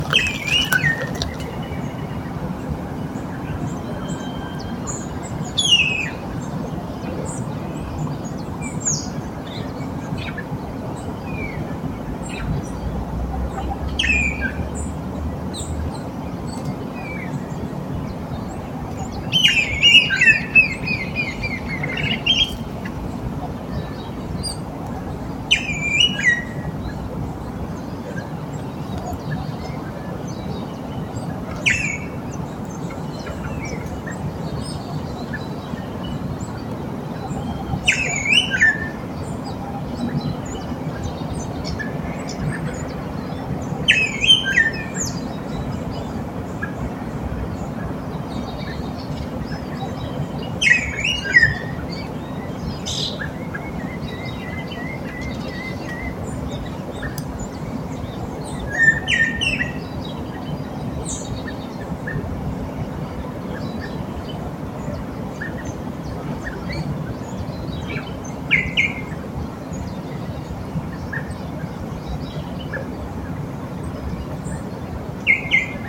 Japiim-preto (Cacicus solitarius)
Nome em Inglês: Solitary Cacique
Detalhada localização: Eco Área Avellaneda
Condição: Selvagem
Certeza: Fotografado, Gravado Vocal